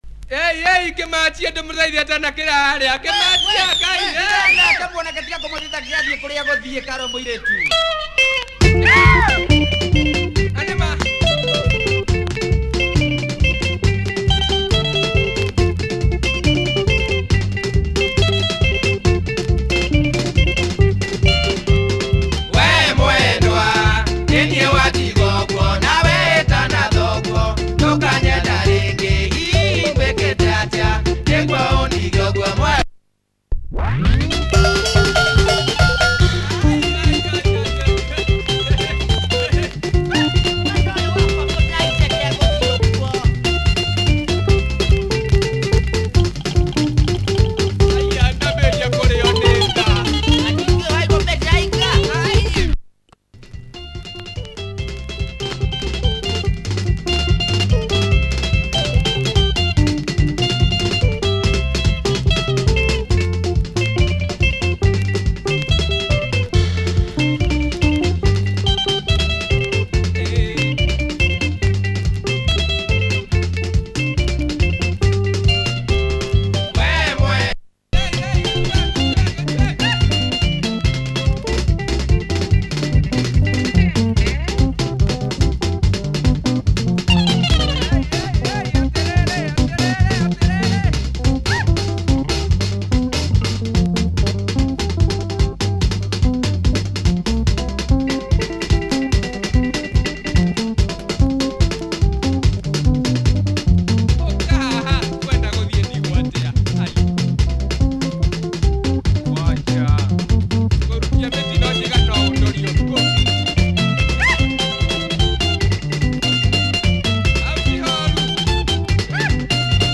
Nice Kikuyu benga by this prolific group. https